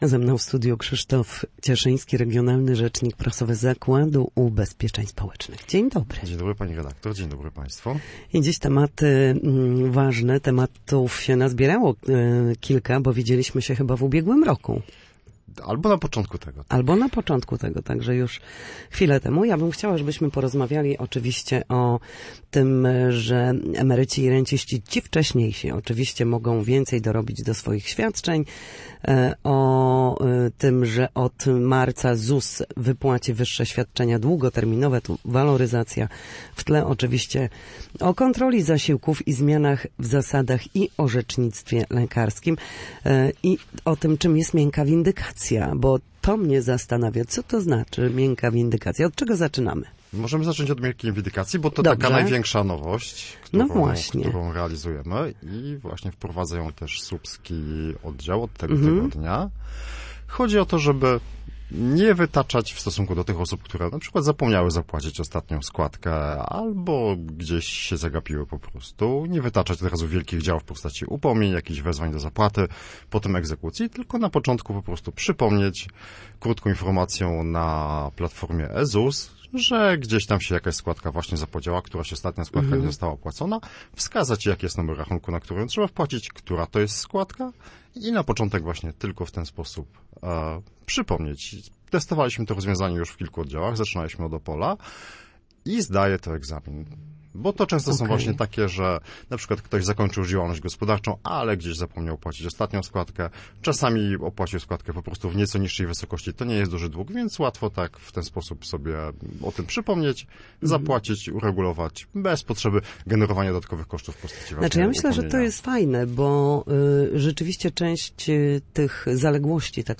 Na naszej antenie mówił o wyższych świadczeniach długoterminowych, kontrolach zasiłków oraz zmianach w zasadach i orzecznictwie lekarskim.